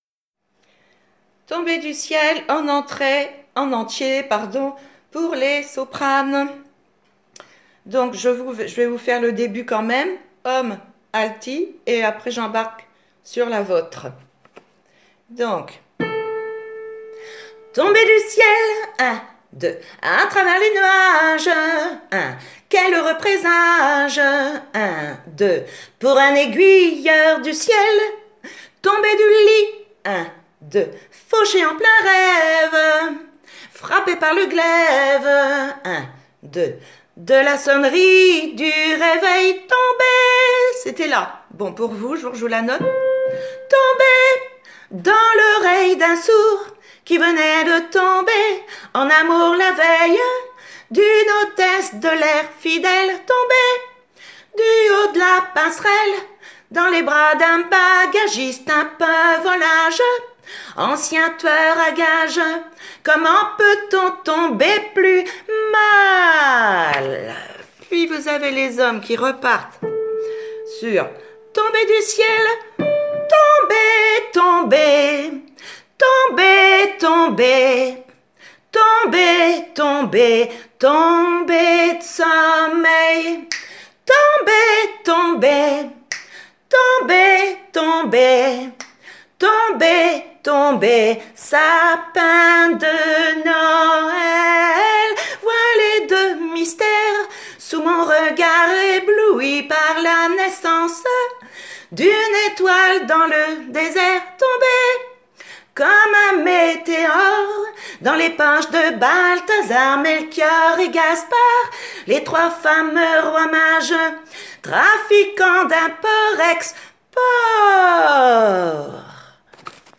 tombe_soprani_entier.mp3